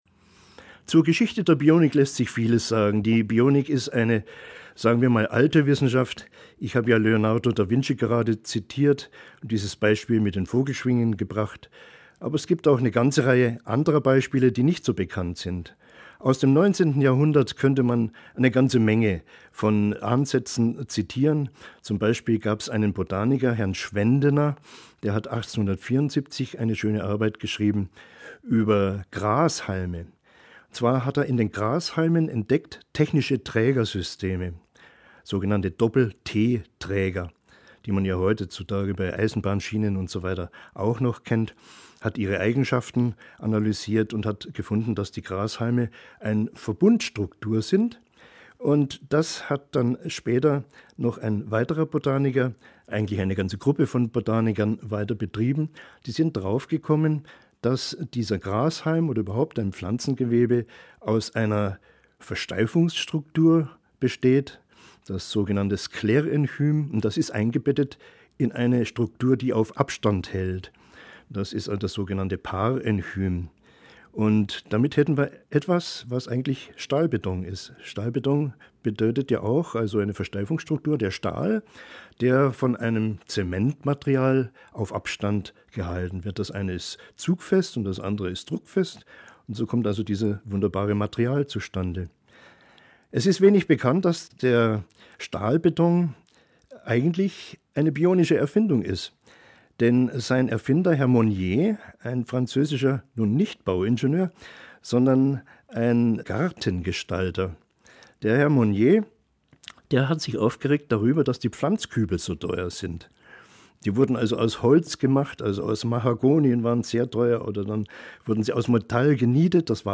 Hörbuch: Bionik - Was ist das?